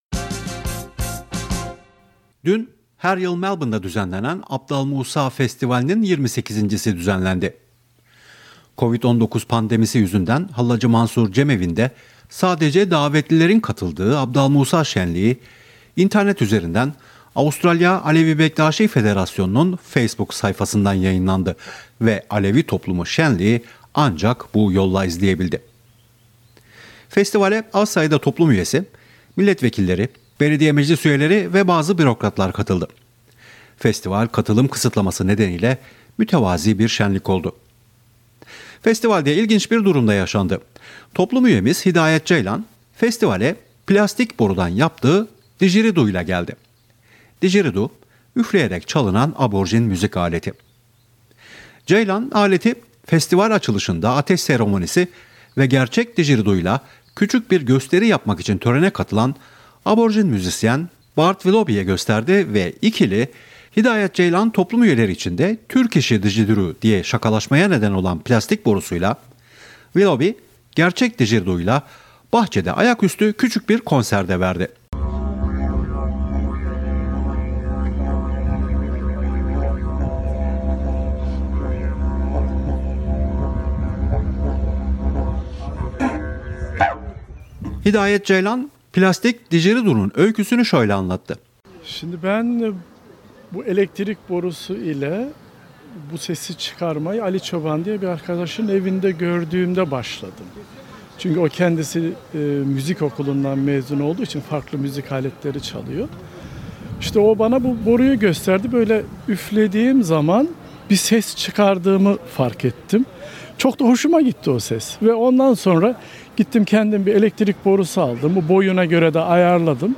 Abdal Musa Festivali: Semah ve Didgeridoo bir arada
28. Abdal Musa Festivali, Hallac-ı Mansur cem Evi, Melbourne, 21 Kasım 2021 Source: SBS